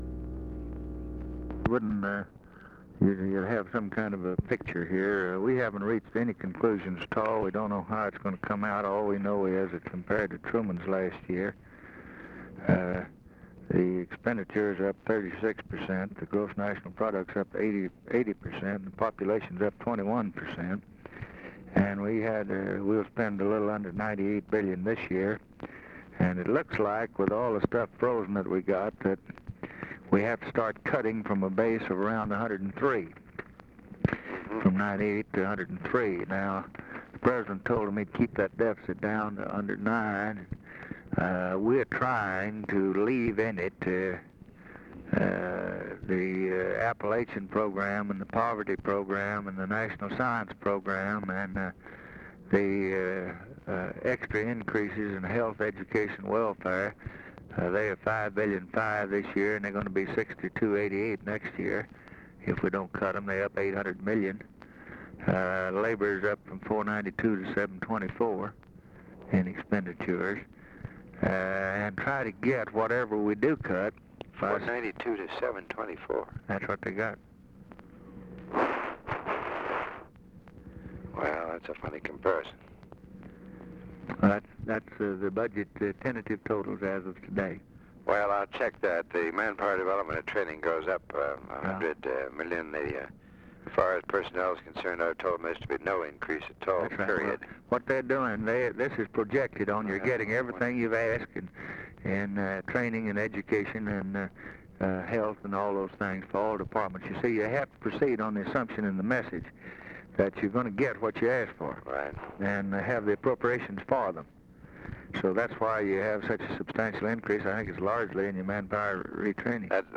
Conversation with WILLARD WIRTZ, December 6, 1963
Secret White House Tapes